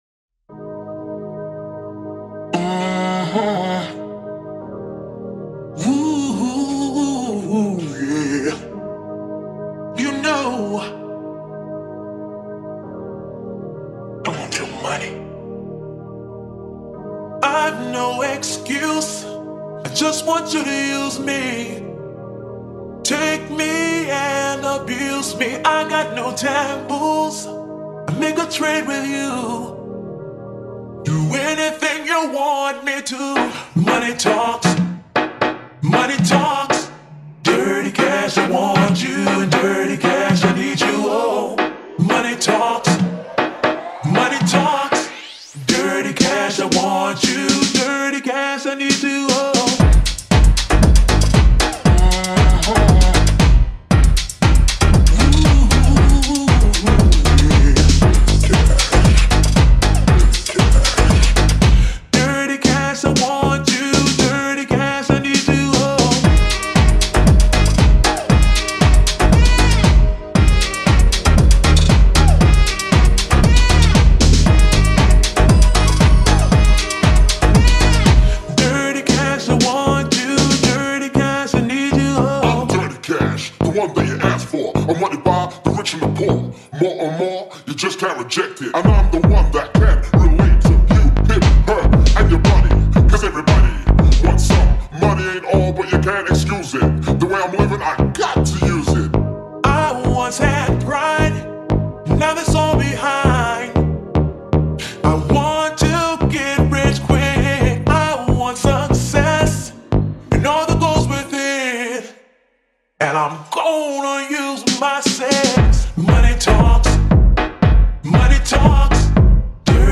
نسخه Slowed و کند شده
شاد